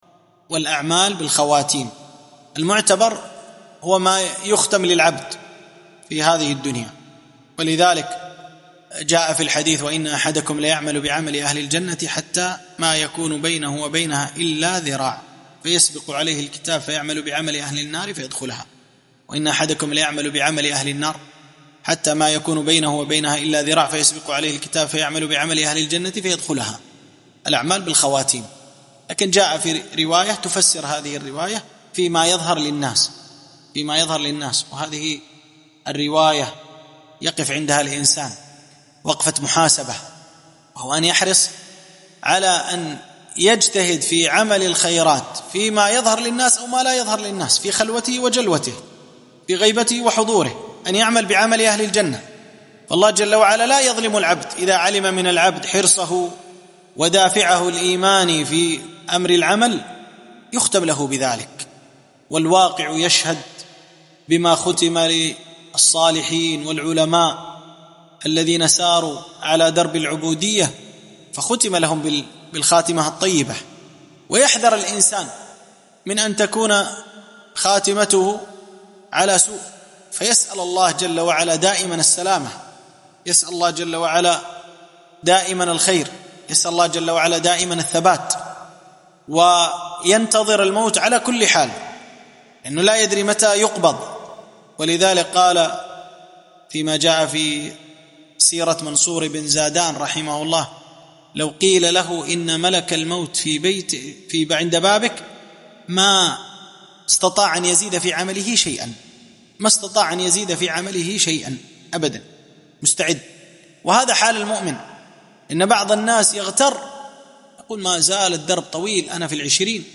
موعظة و تذكرة بأن العبرة بالخواتيم